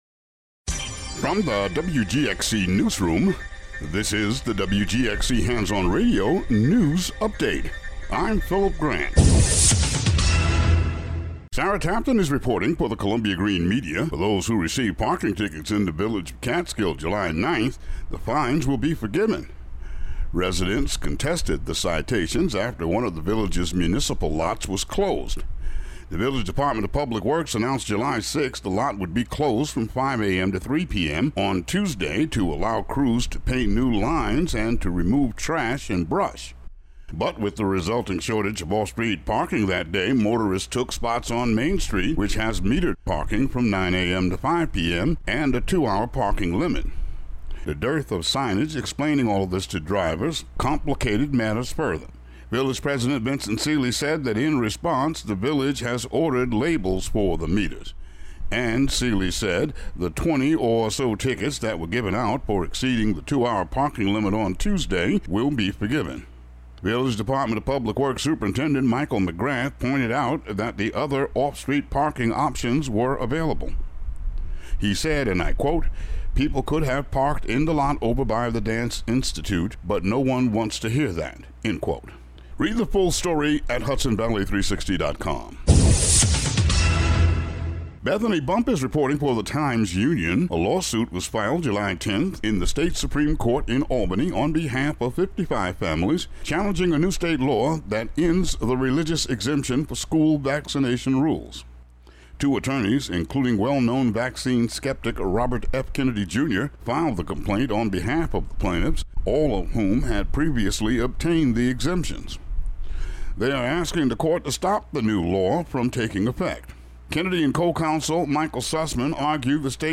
Local news update for WGXC.